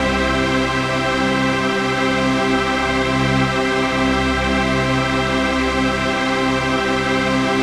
RAVEPAD 02-LR.wav